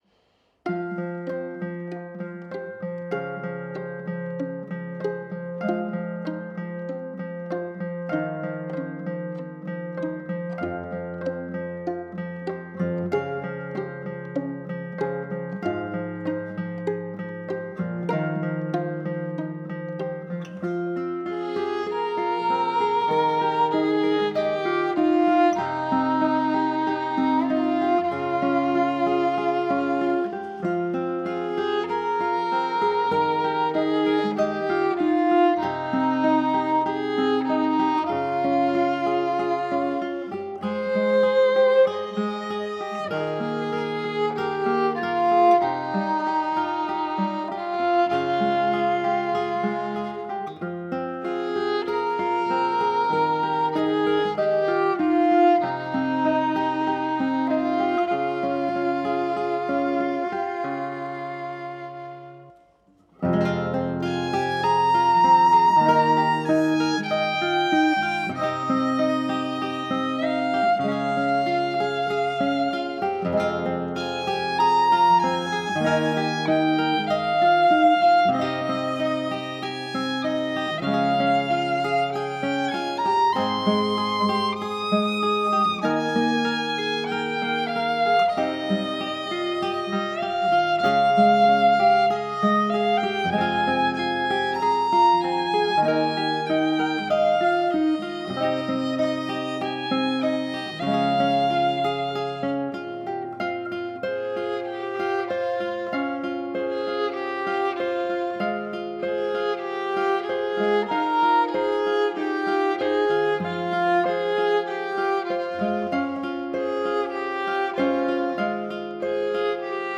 Violin
Guitar